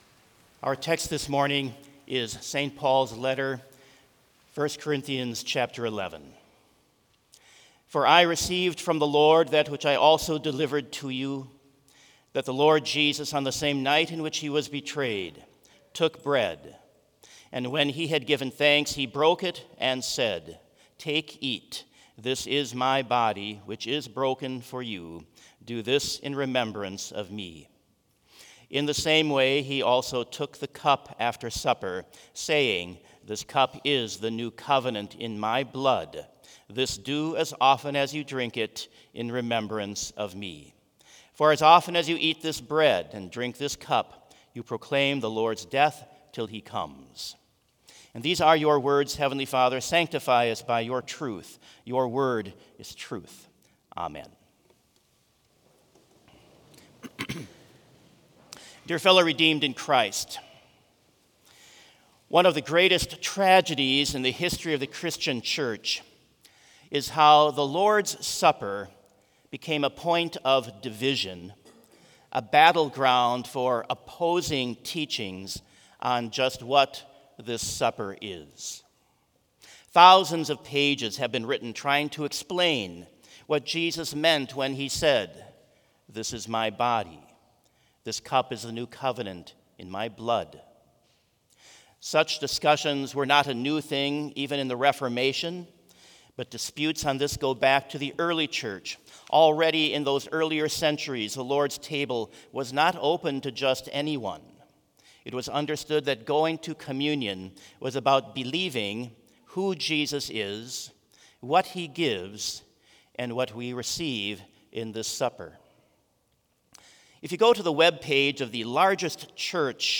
Sermon Only
This Chapel Service was held in Trinity Chapel at Bethany Lutheran College on Wednesday, April 16, 2025, at 10 a.m. Page and hymn numbers are from the Evangelical Lutheran Hymnary.